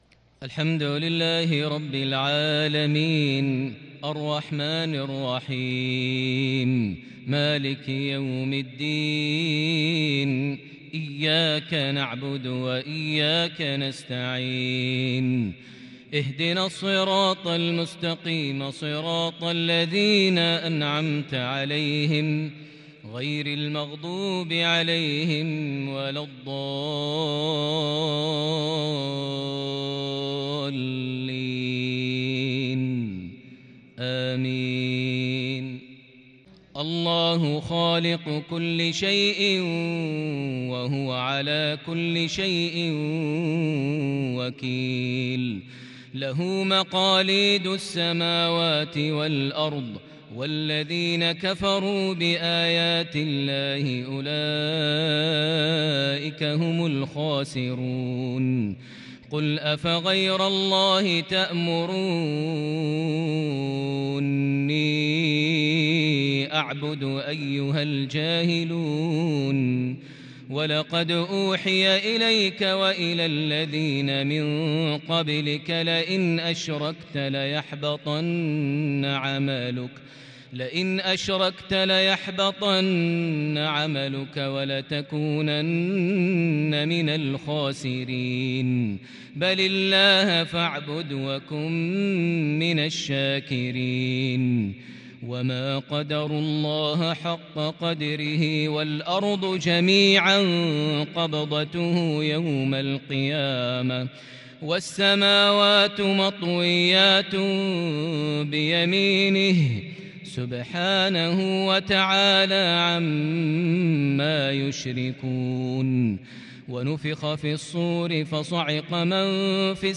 عشائية متألقة بتحبيرالكرد لخواتيم سورة الزمر |4 ذي الحجة 1442هـ > 1442 هـ > الفروض - تلاوات ماهر المعيقلي